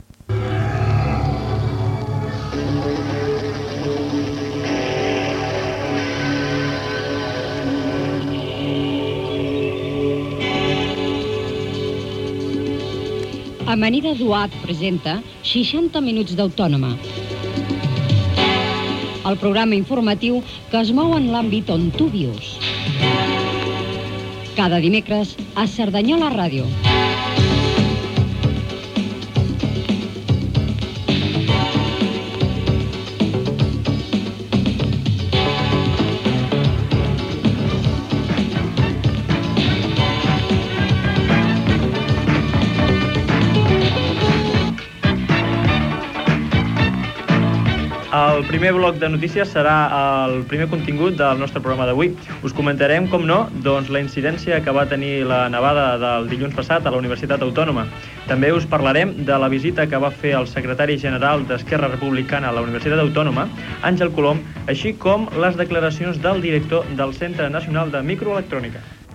Careta del programa i sumari: nevada a la Universitat Autònoma de Barcelona, visita d'Àngel Colom a la UAB, etc.
Informatiu